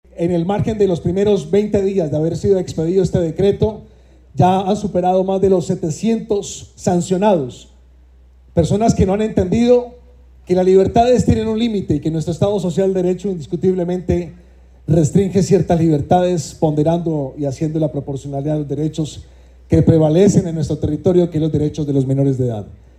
Cristian Portilla, alcalde de Bucaramanga